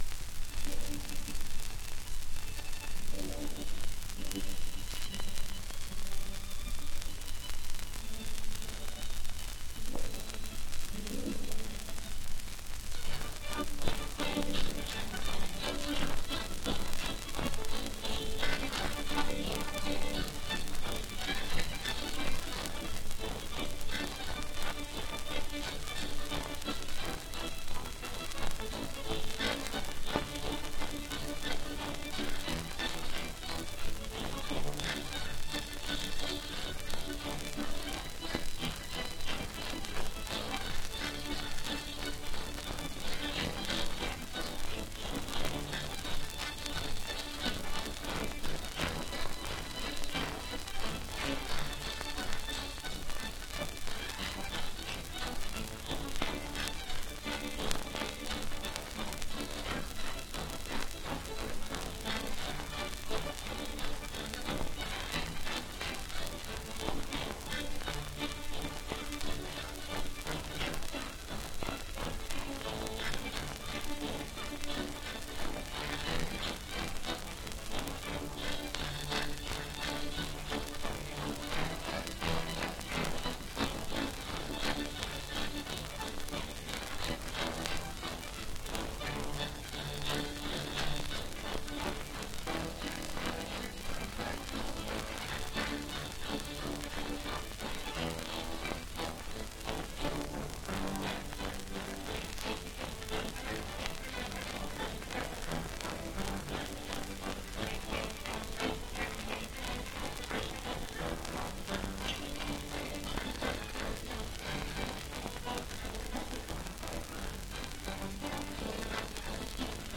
1 10 inch 78rpm shellac disc
S1 Highland Schottische
S2 Irish Reels